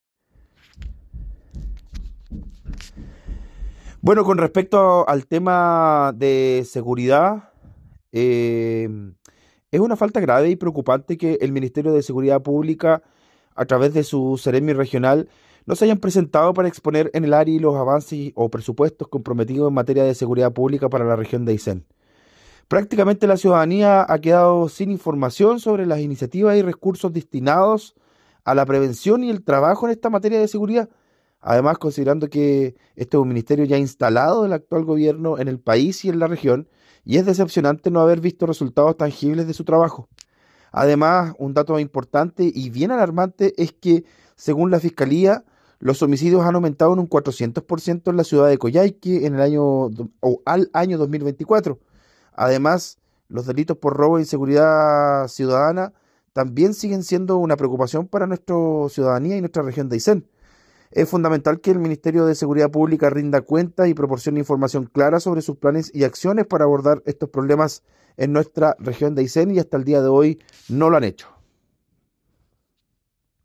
Consejero Pablo Guglielmi